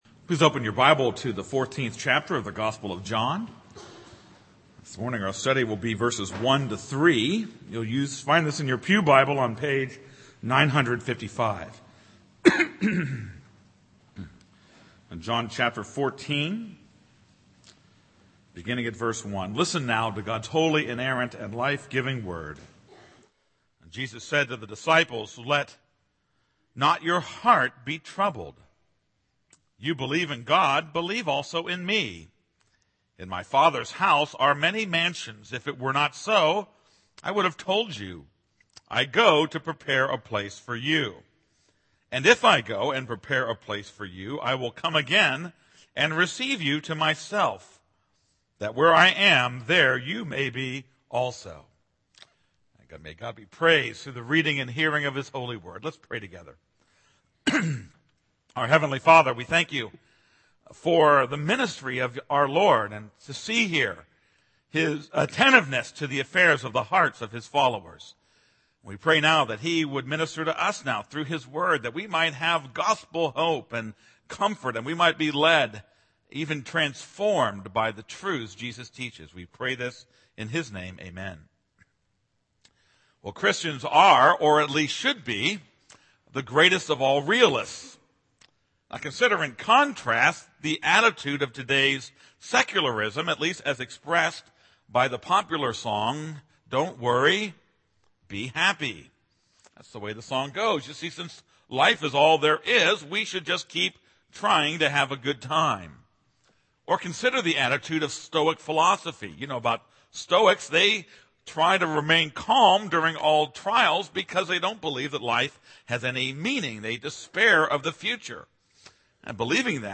This is a sermon on John 14:1-3.